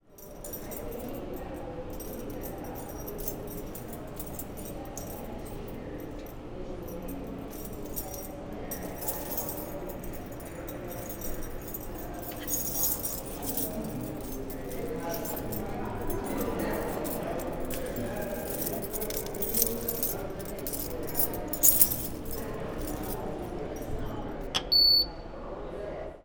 Sound file 1.5 The sounds of an officer making his rounds